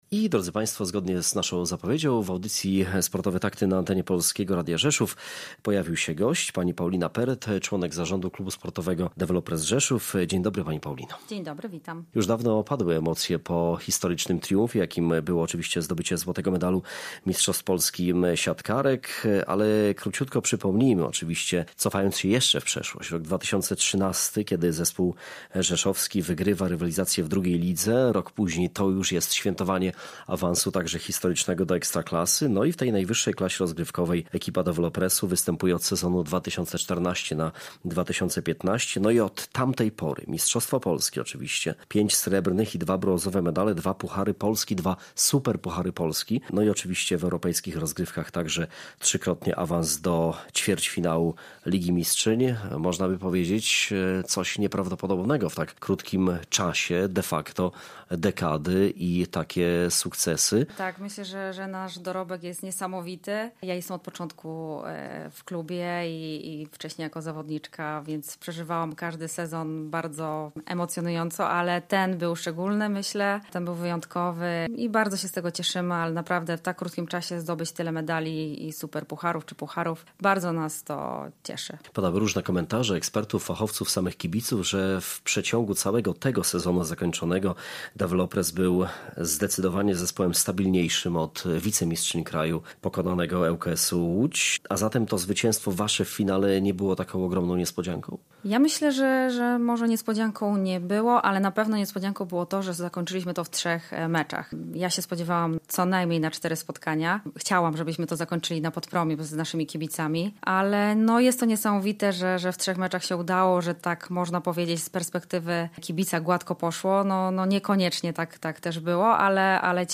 Gościem sobotniej audycji „Sportowe Takty”